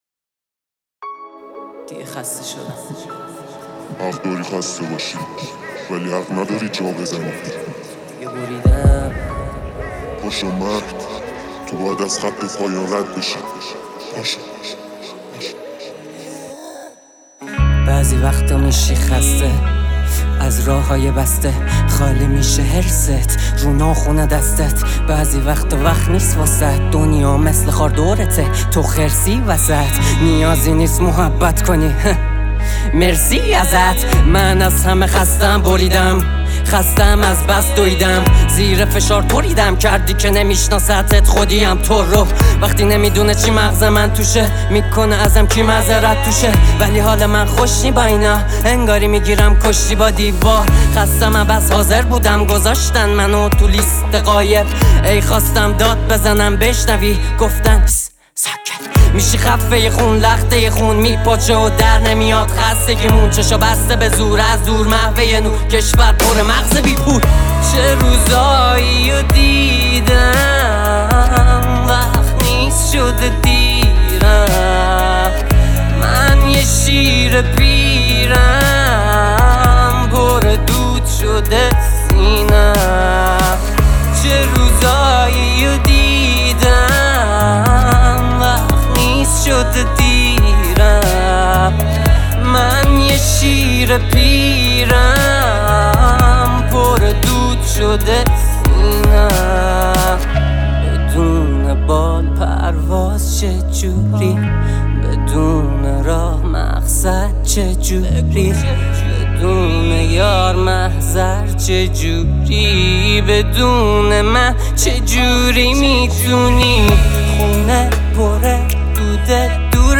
دانلود آهنگ رپ